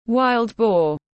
Thịt lợn rừng tiếng anh gọi là wild boar, phiên âm tiếng anh đọc là /waɪld bɔː/